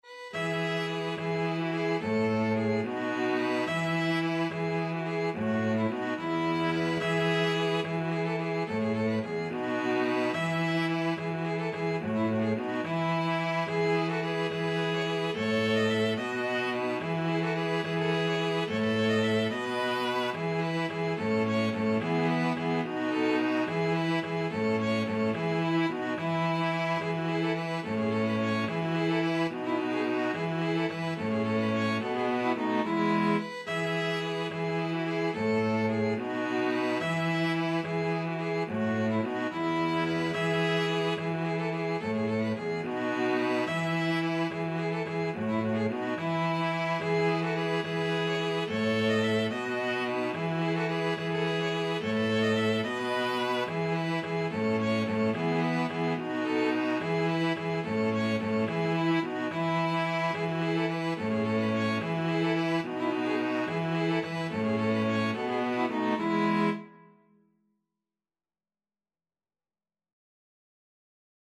Free Sheet music for String trio
ViolinViolaCello
E major (Sounding Pitch) (View more E major Music for String trio )
6/4 (View more 6/4 Music)
Traditional (View more Traditional String trio Music)